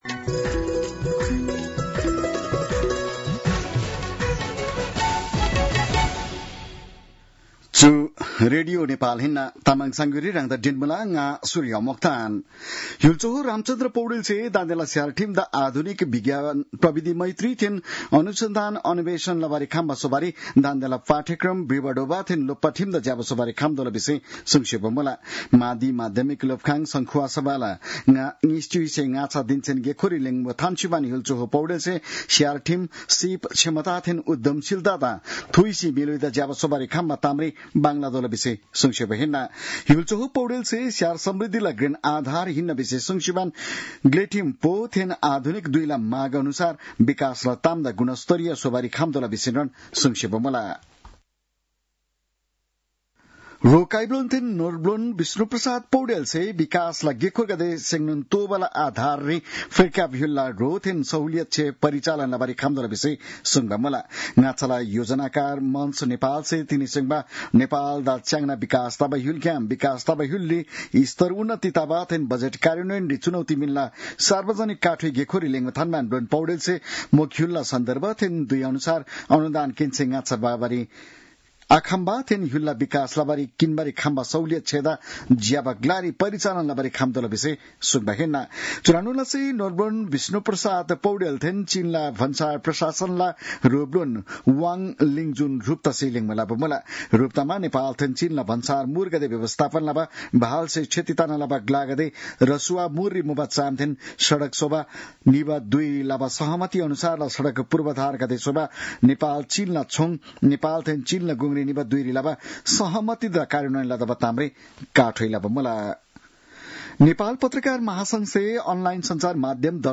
तामाङ भाषाको समाचार : १२ भदौ , २०८२